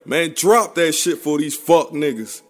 Tm8_Chant13.wav